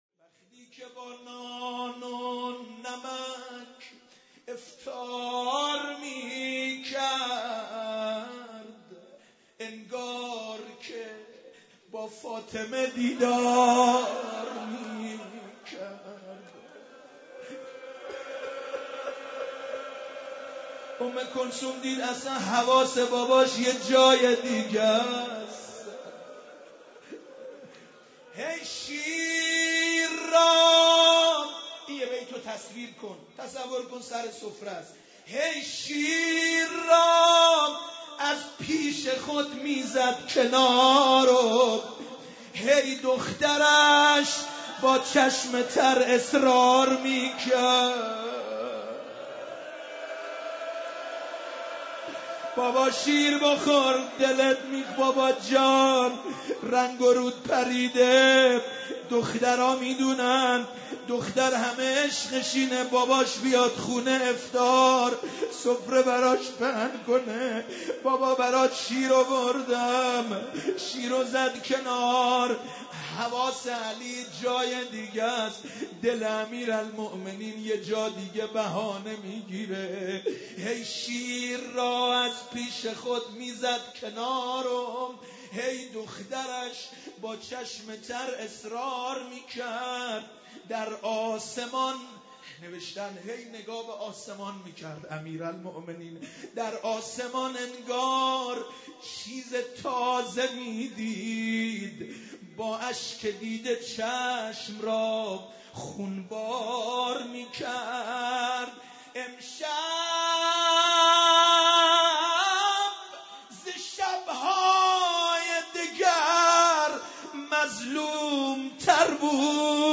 مداحی
در هیئت رزمندگان اسلام قم برگزار گردید.